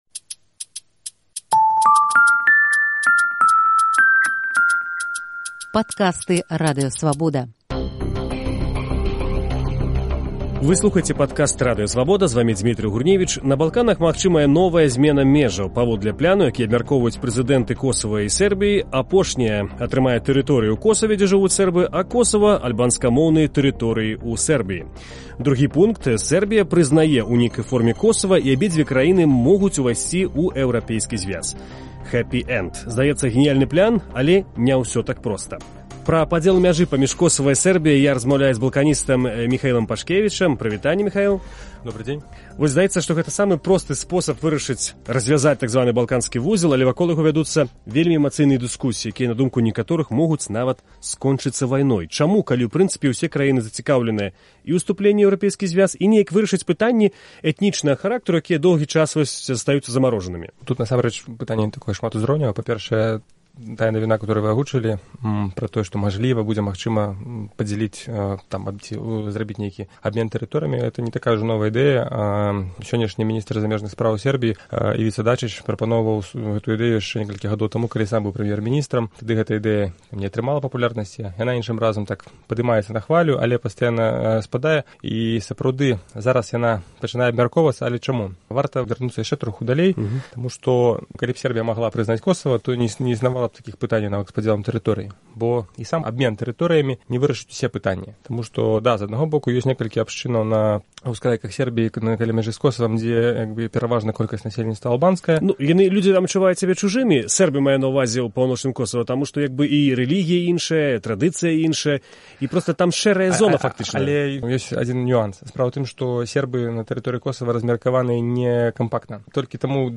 Гутарка